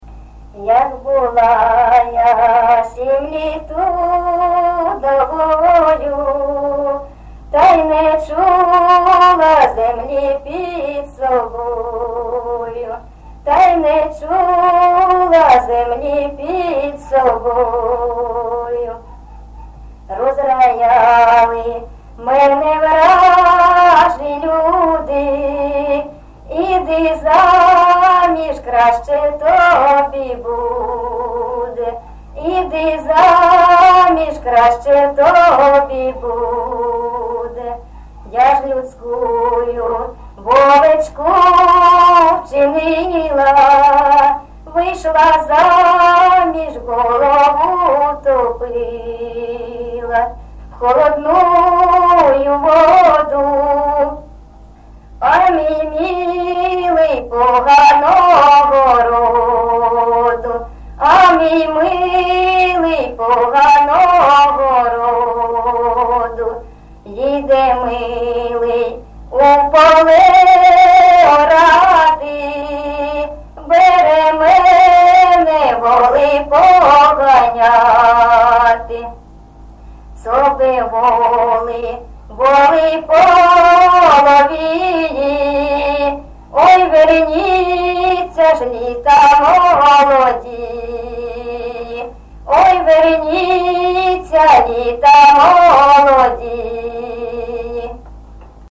Місце записус-ще Троїцьке, Сватівський район, Луганська обл., Україна, Слобожанщина